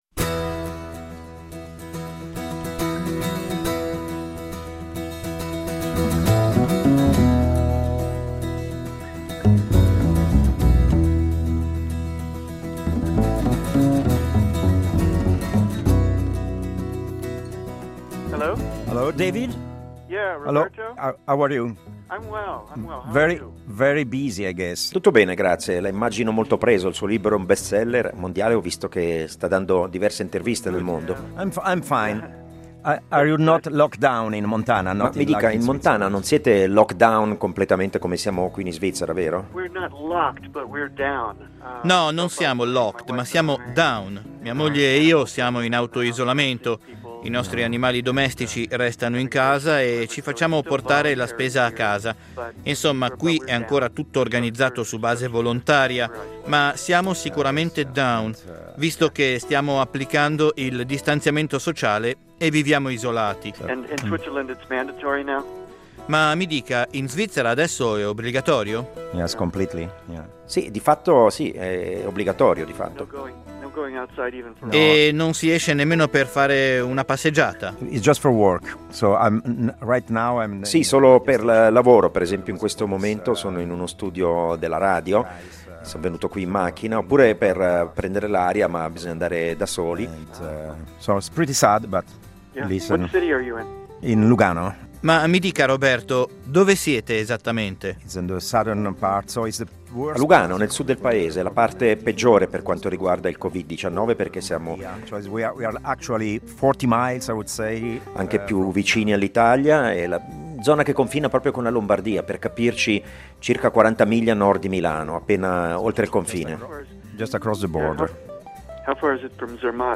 Incontro con David Quammen